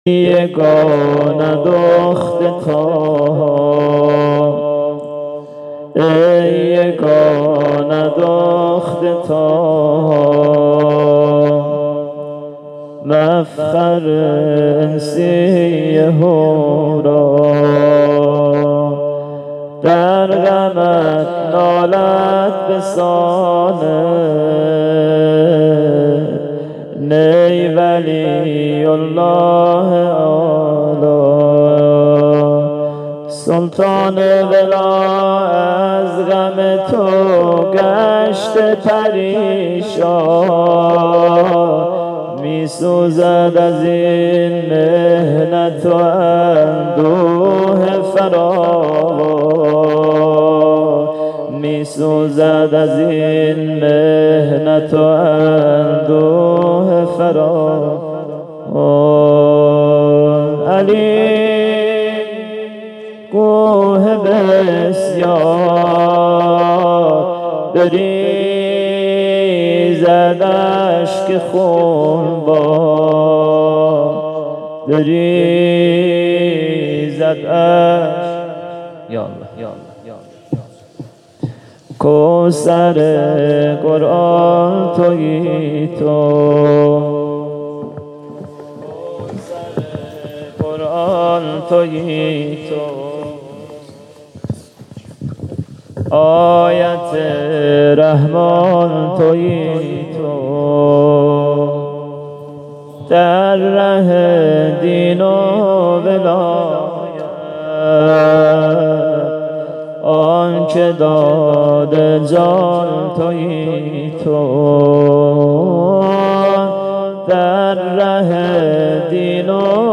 فاطمیه 97 ( اول ) - شب سوم - بخش اول سینه زنی